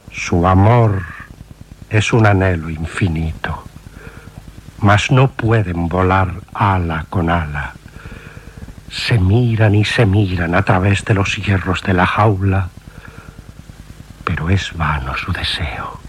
Extret de Crònica Sentimental de Ràdio Barcelona emesa el dia 29 d'octubre de 1994.